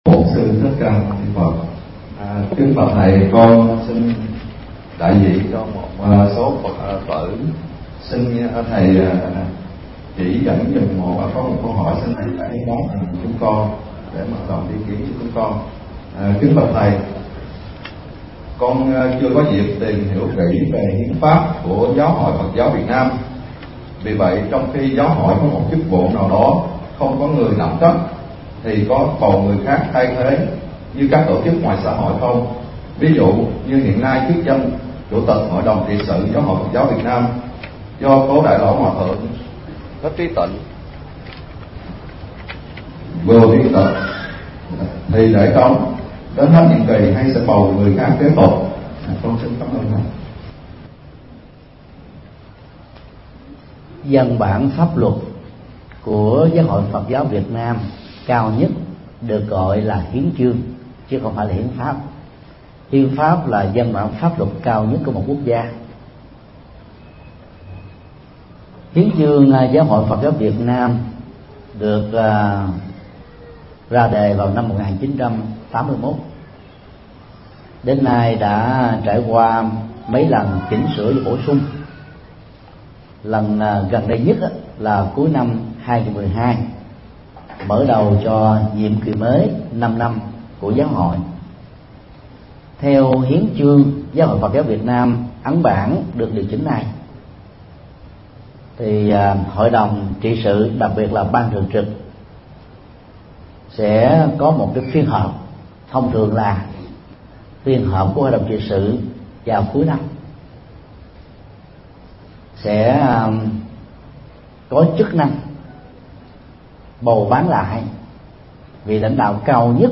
Vấn đáp: Hiến chương Giáo hội Phật giáo Việt Nam